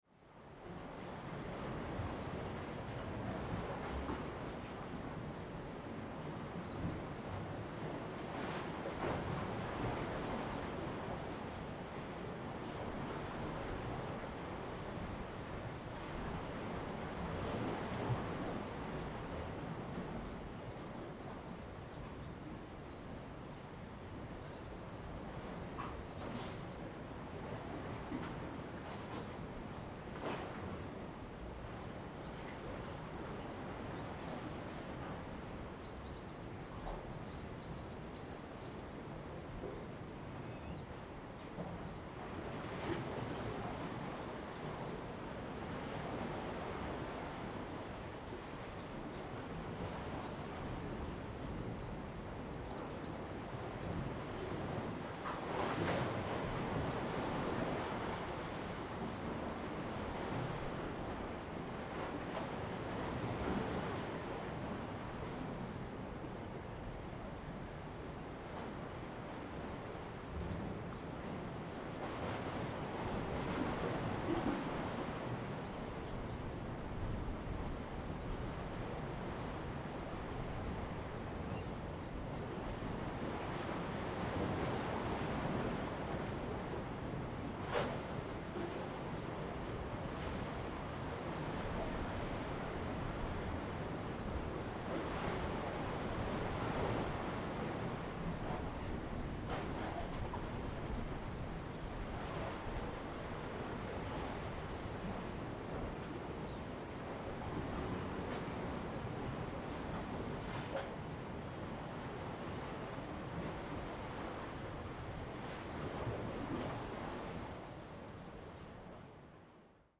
On the bank, Tide and WaveBest with Headphone, Proposal to turn up the volume ,Zoom H2n MS+XY